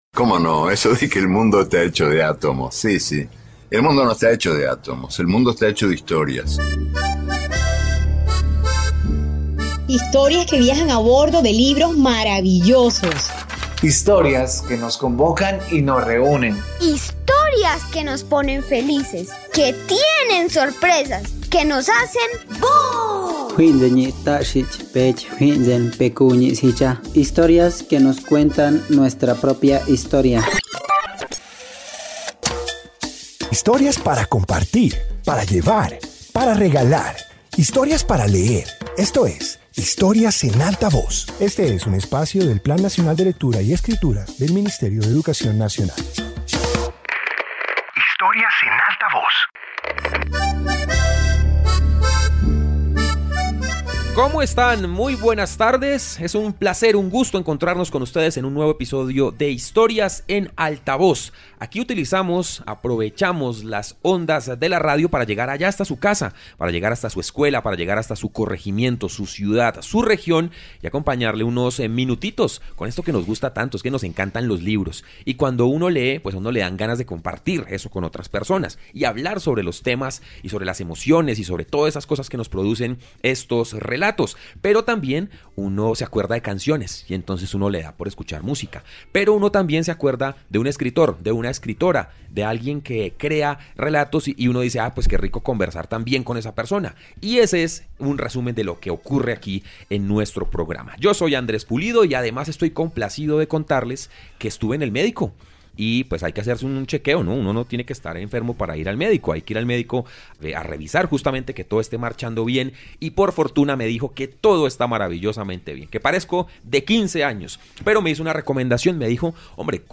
Introducción Este episodio de radio presenta relatos y datos sobre minerales, su formación y presencia en la vida cotidiana. Incluye historias que muestran su valor natural y cultural, así como curiosidades que permiten comprender mejor su importancia.